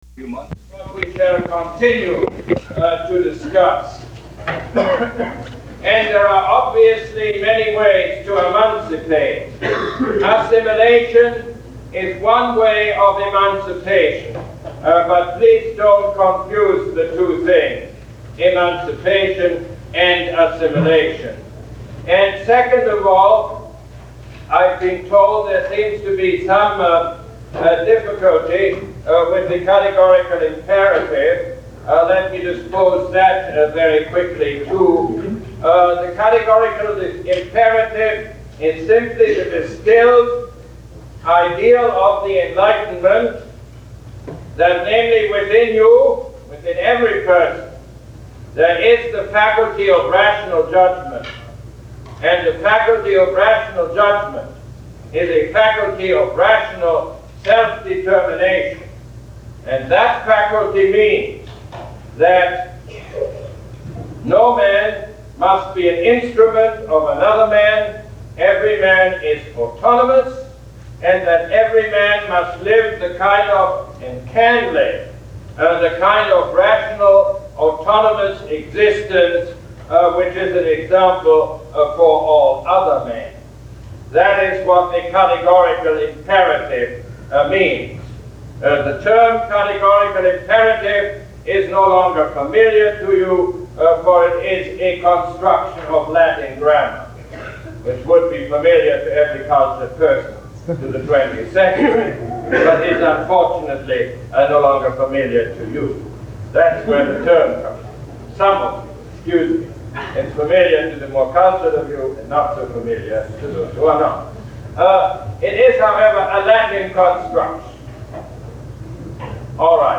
Lecture #4 - February 19, 1971